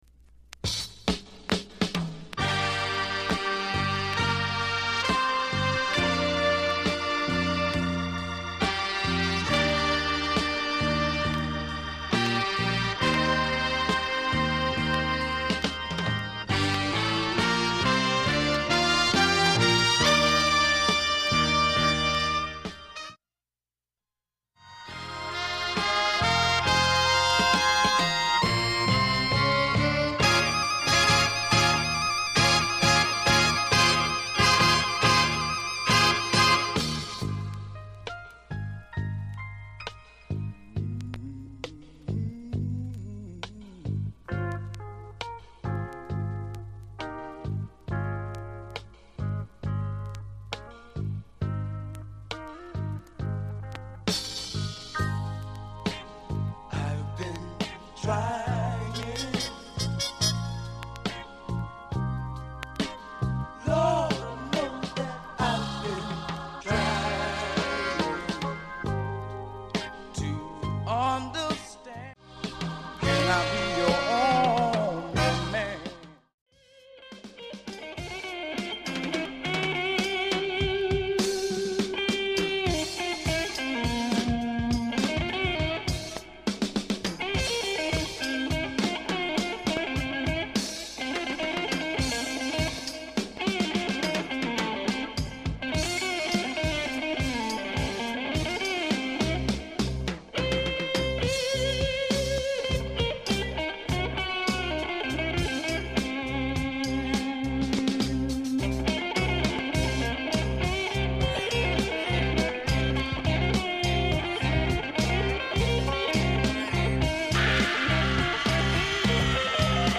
A-2始めわずかなプツ数回2か所入ります。
ほか音圧、音質ともにかなり良好です。
バックチリなどもほとんどありません。
ステレオ針での試聴です。
かなり珍しいモノラルオンリー